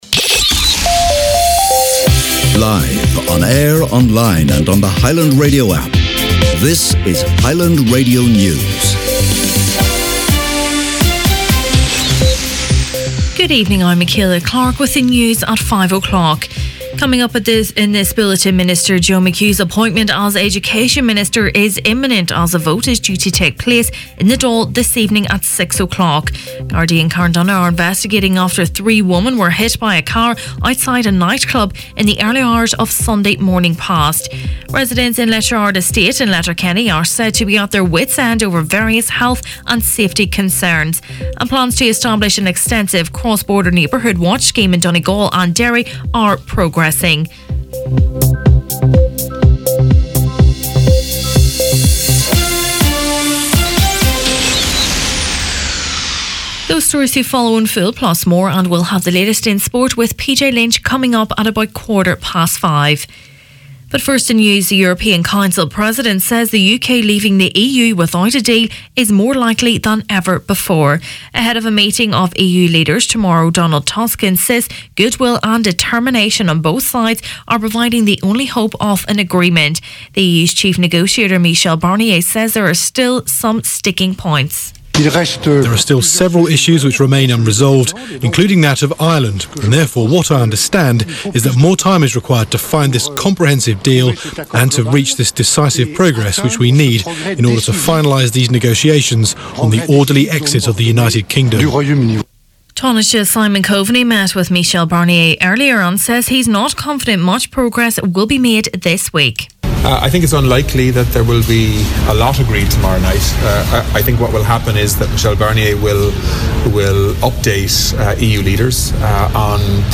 Main Evening News, Sport and Obituaries Tuesday October 16th